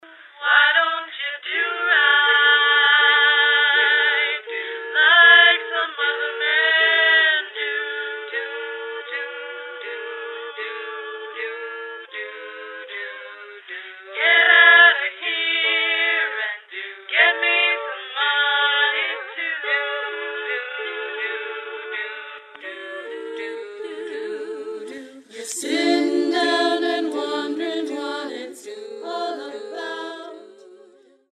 (SSAA)